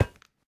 Minecraft Version Minecraft Version snapshot Latest Release | Latest Snapshot snapshot / assets / minecraft / sounds / block / iron / step4.ogg Compare With Compare With Latest Release | Latest Snapshot
step4.ogg